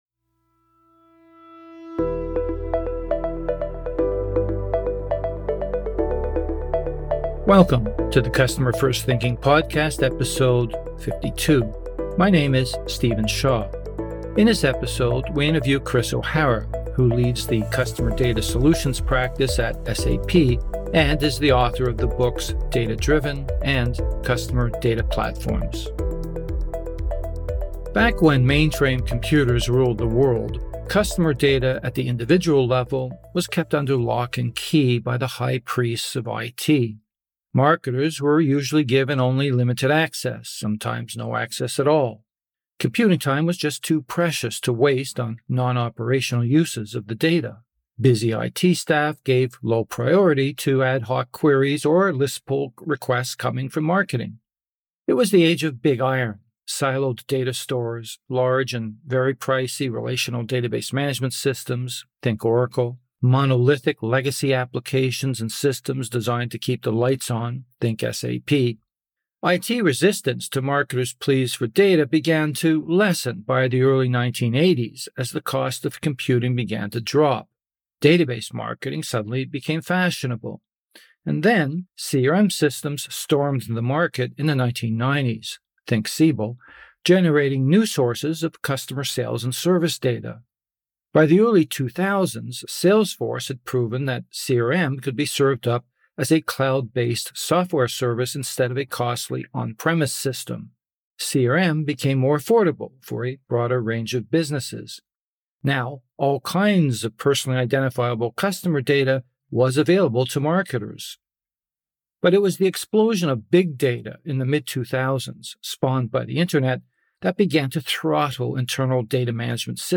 This interview has been edited for length and clarity.